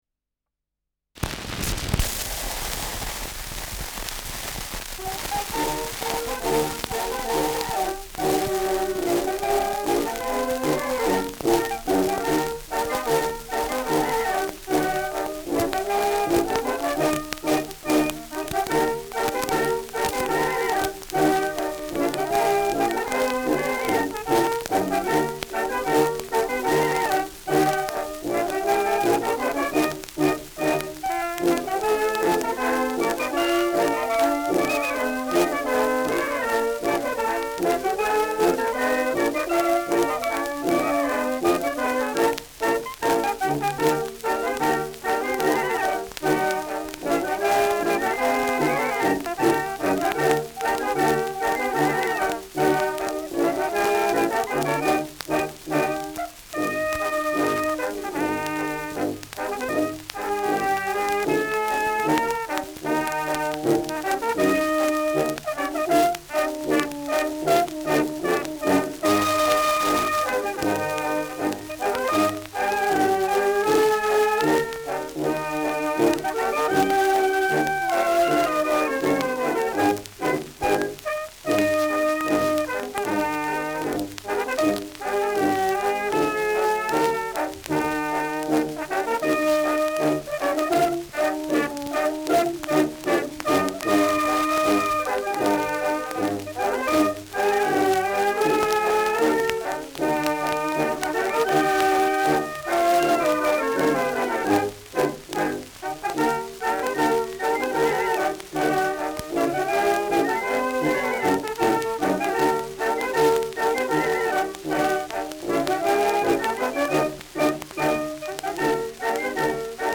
Schellackplatte
Stärkeres Grundknistern : Durchgehend leichtes bis stärkeres Knacken : Verzerrt an lauten Stellen : Springt gegen Ende